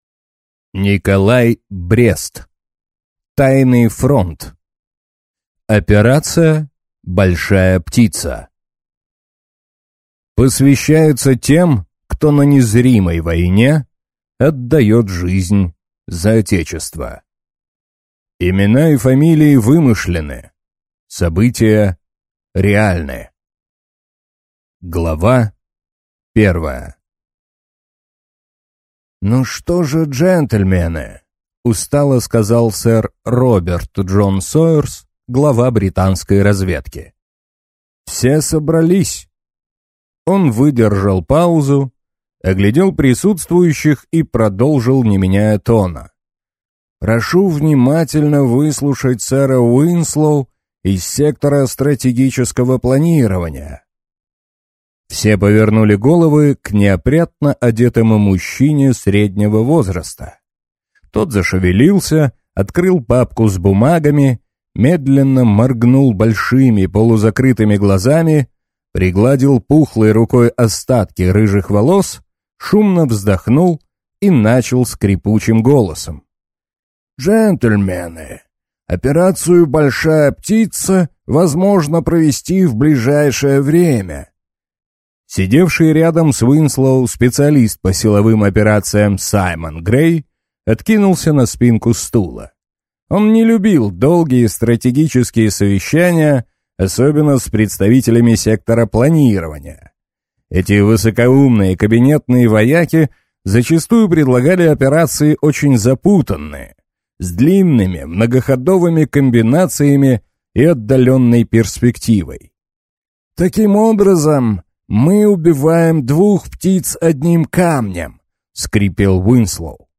Аудиокнига Тайный фронт. Операция «Большая птица» | Библиотека аудиокниг